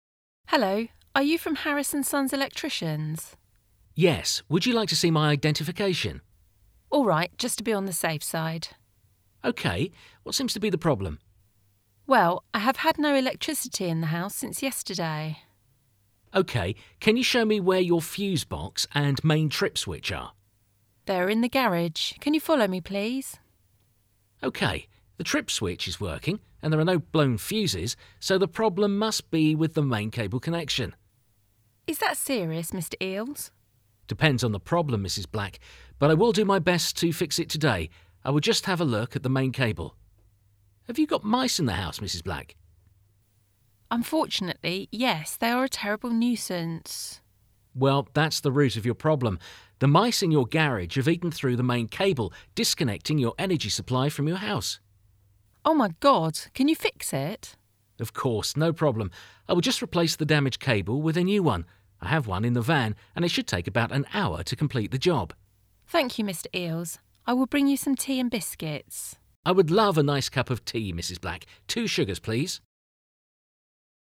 Hasznos párbeszédek – Villanyszerelő hívása
Hasznos párbeszéd letölthető hanganyaggal, arra az esetre, ha villanyszerelőt kellene hívnunk külföldön.